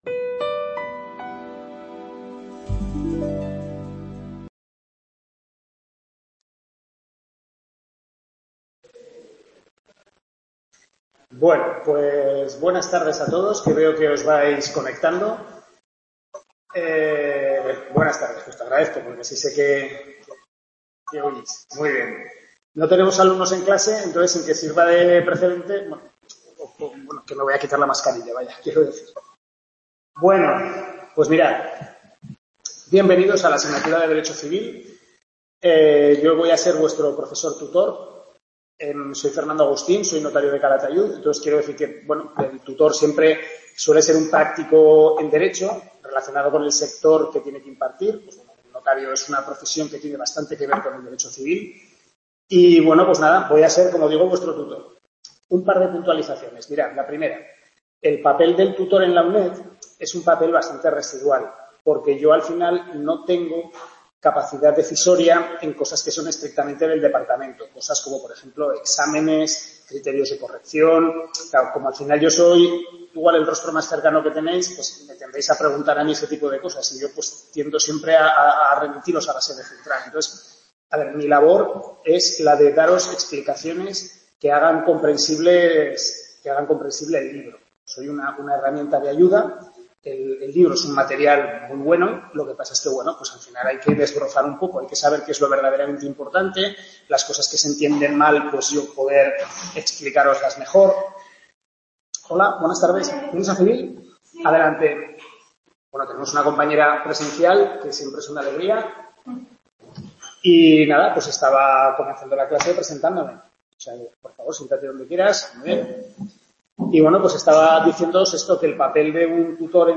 Tutoría de Civil I, centro de Calatayud, capítulos 1-4 del Manual del Profesor Lasarte